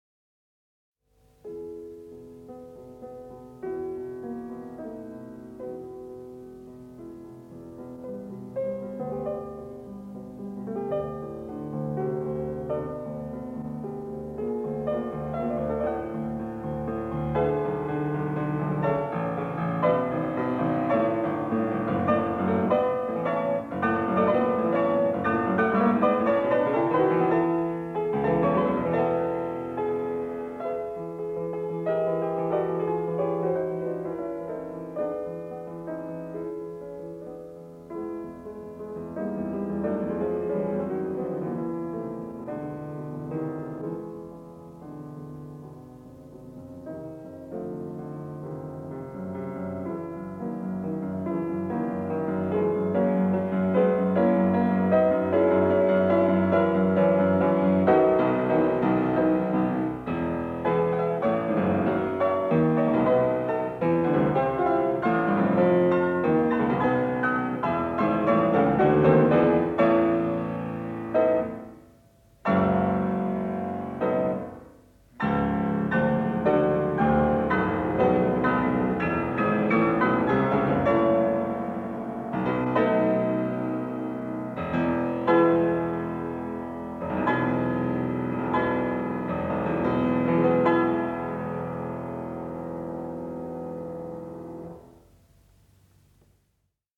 Näyte 6. Brahms: Pianosonaatti f-molli op.5 osa 1 tahdit 161-190, Jyväskylä 1970